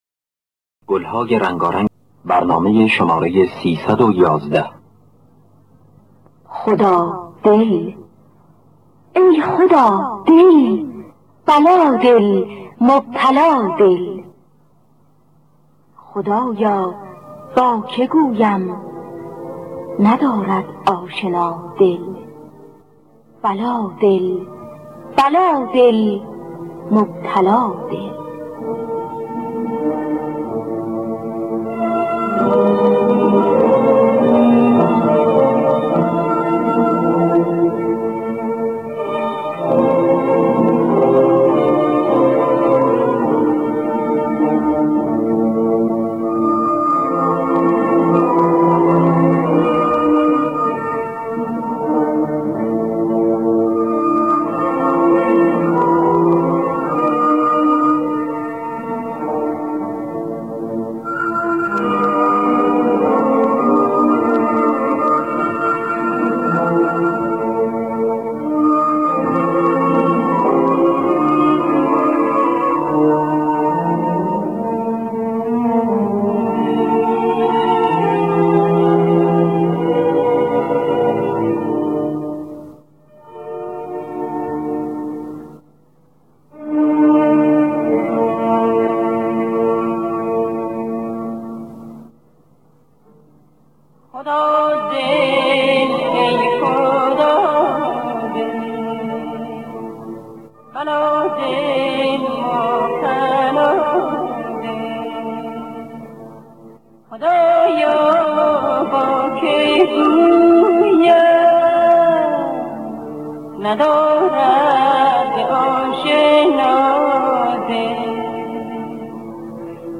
خوانندگان: پوران عبدالوهاب شهیدی نوازندگان: روح‌الله خالقی